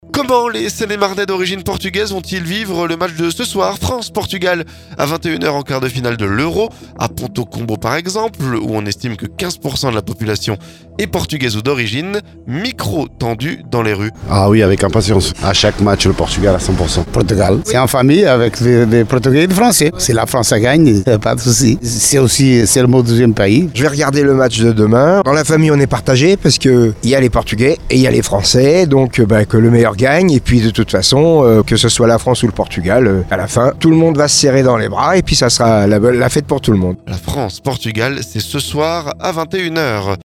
FRANCE-PORTUGAL - Reportage à Pontault-Combault, la plus portugaise des villes de Seine-et-Marne
Micro tendu dans les rues.